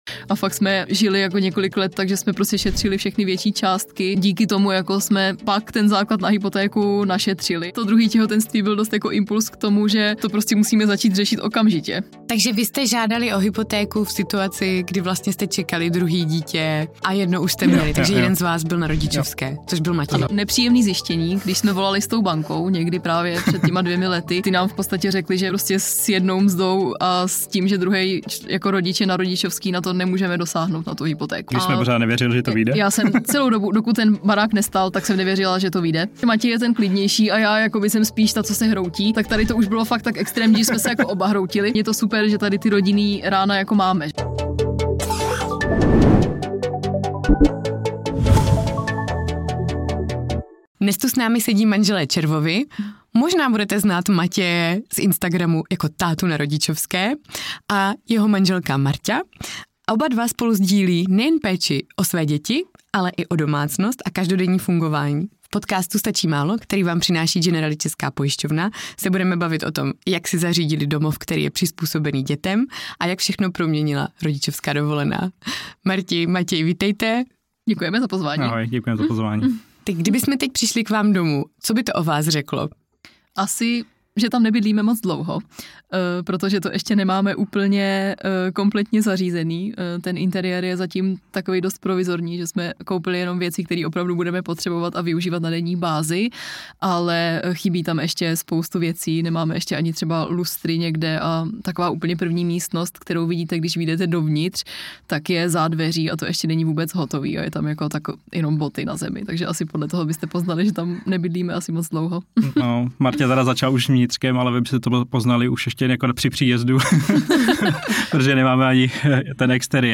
🎙 Nenechte si ujít upřímný rozhovor o tom, co všechno promění jedno rozhodnutí.